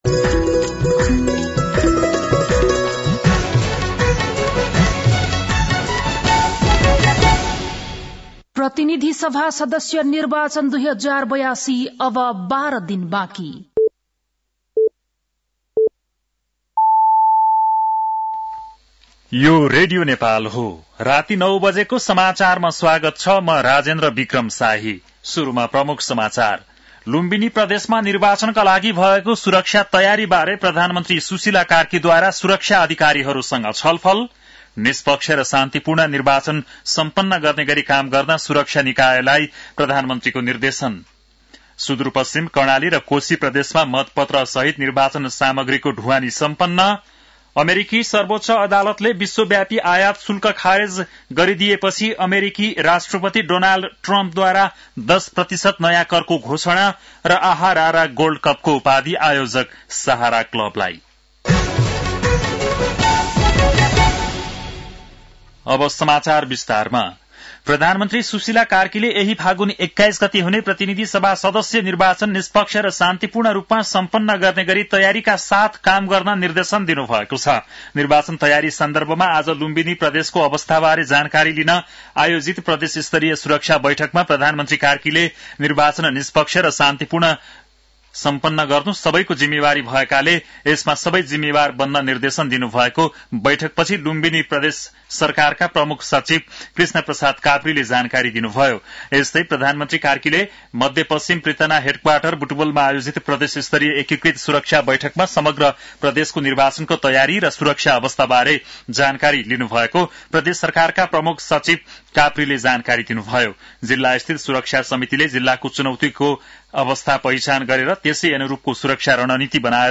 An online outlet of Nepal's national radio broadcaster
बेलुकी ९ बजेको नेपाली समाचार : ९ फागुन , २०८२